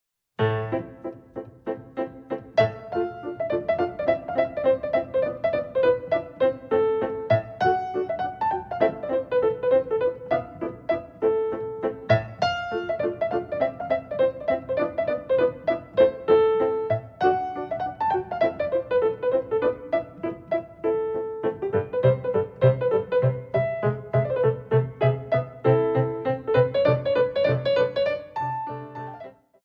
Gigue
Ballet Class Music For First Years of Ballet